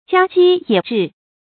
家雞野雉 注音： ㄐㄧㄚ ㄐㄧ ㄧㄜˇ ㄓㄧˋ 讀音讀法： 意思解釋： 雉：野雞。